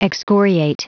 Prononciation du mot excoriate en anglais (fichier audio)
Vous êtes ici : Cours d'anglais > Outils | Audio/Vidéo > Lire un mot à haute voix > Lire le mot excoriate